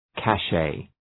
Προφορά
{kæ’ʃeı}